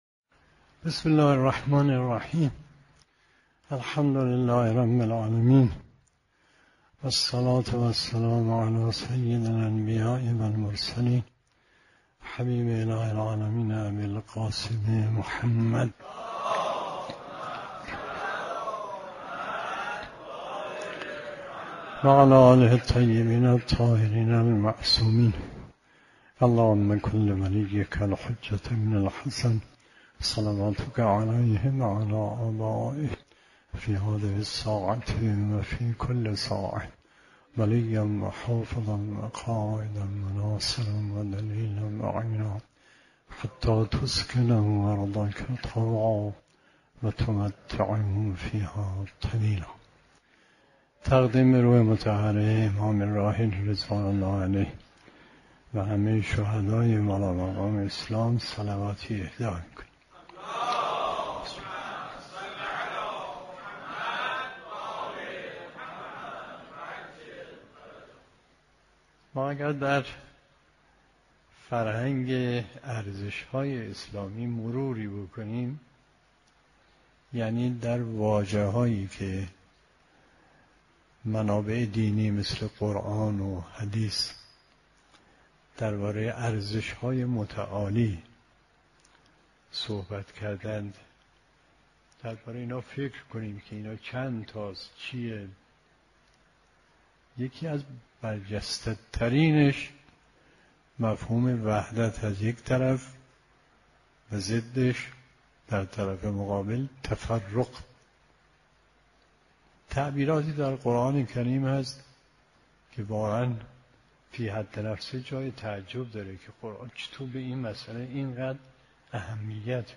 سخنرانی آیت الله مصباح یزدی درباره لزوم شبکه‌سازی نیروهای مؤمن در سراسر کشور